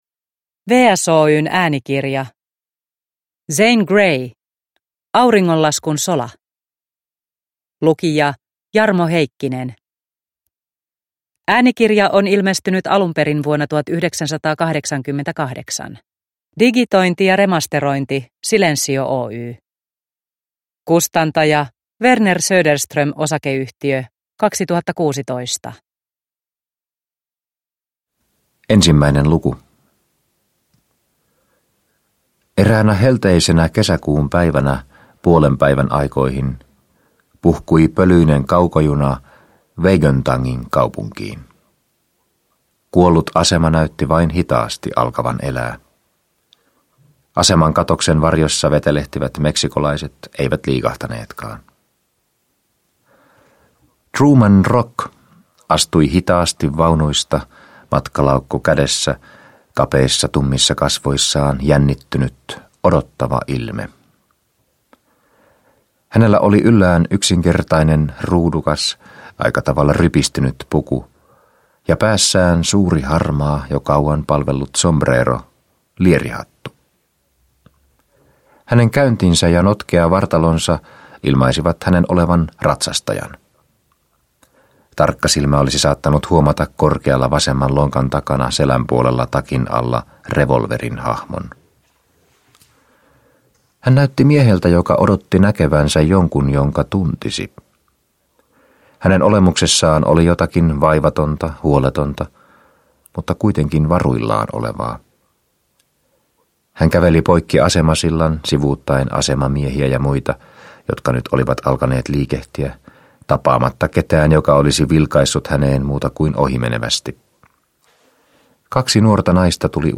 Auringonlaskun sola – Ljudbok – Laddas ner